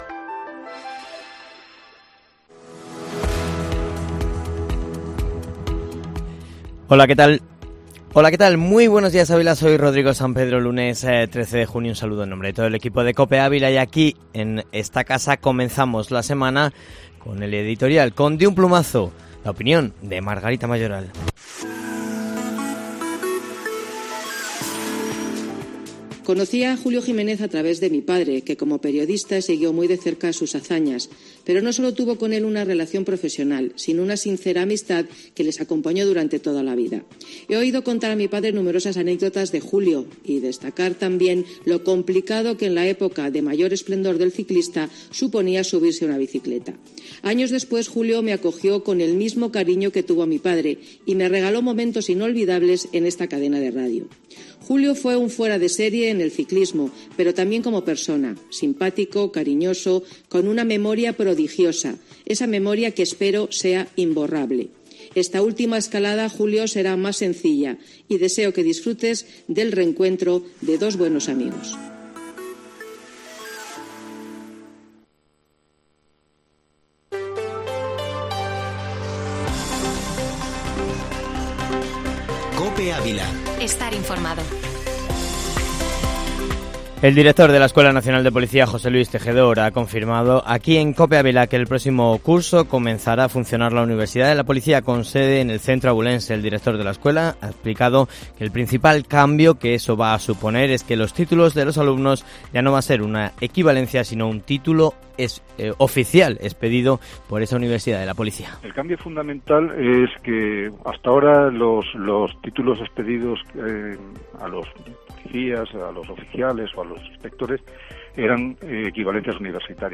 Informativo Matinal Herrera en COPE Ávila -13-junio